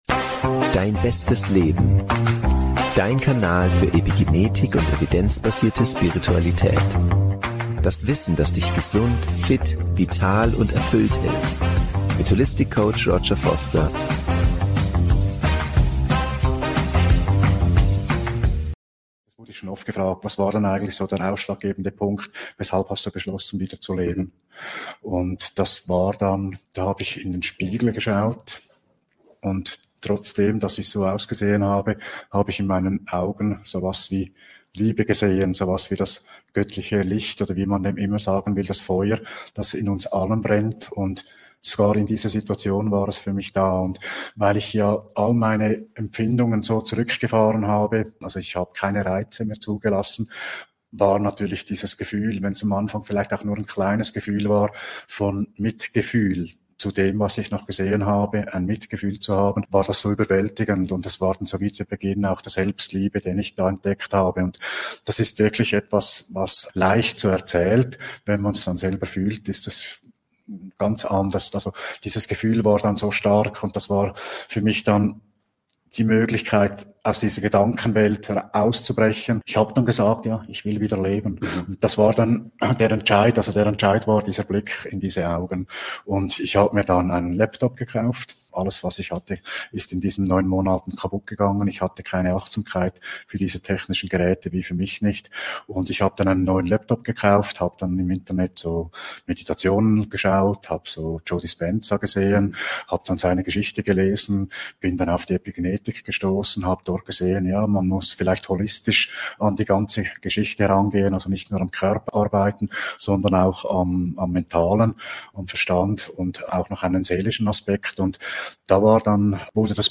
Expertentalk: Mit Epigenetik aus dem Irrenhaus in die Selbstheilung... ~ Dein bestes Leben: Evidenzbasierte Spiritualität und Epigenetik Podcast